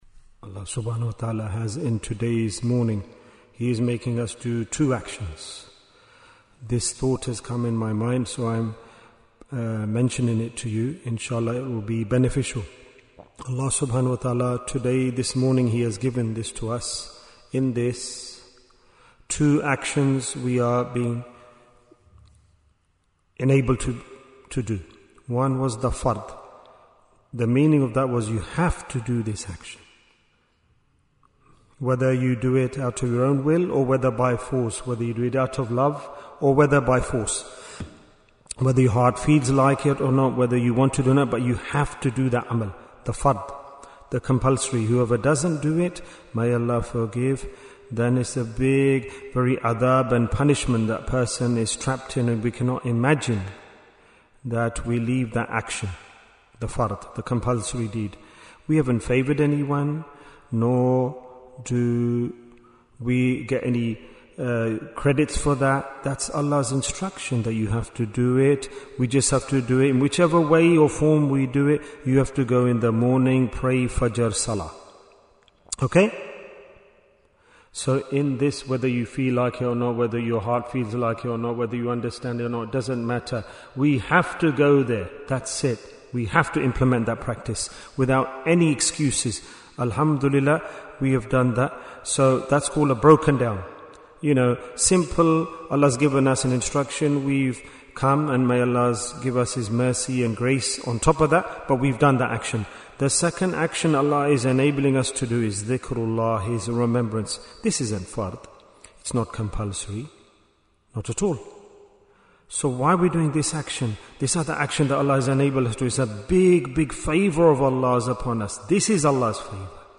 - Part 2 Bayan, 38 minutes 12th January, 2026 Click for Urdu Download Audio Comments Why is Tazkiyyah Important?